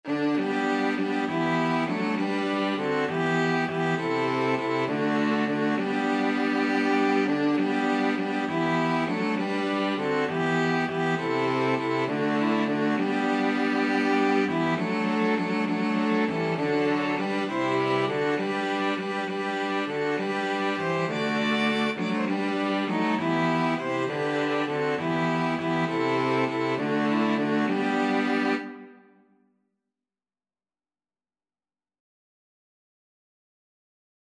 Violin 1Violin 2ViolaCello
6/8 (View more 6/8 Music)
String Quartet  (View more Easy String Quartet Music)
Christmas (View more Christmas String Quartet Music)